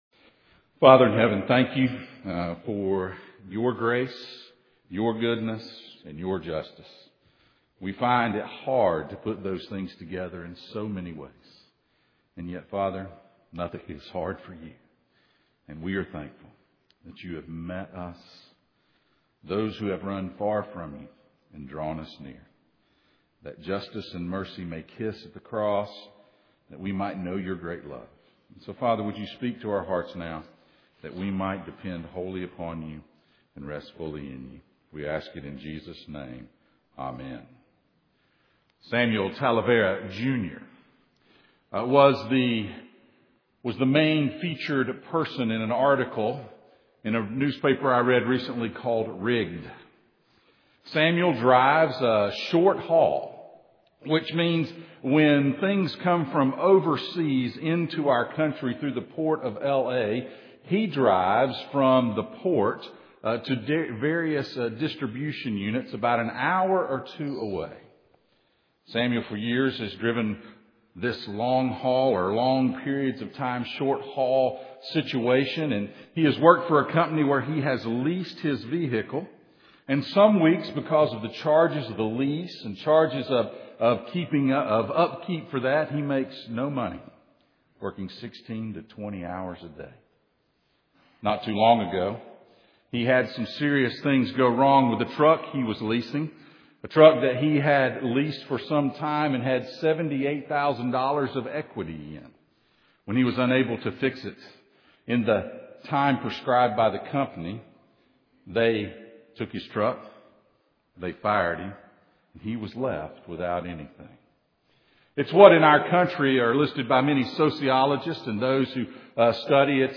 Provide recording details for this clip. A Major In The Minors Passage: Amos 2:6-8, Amos 5:4-7, Amos 5:18-27, Amos 9:11-15 Service Type: Sunday Morning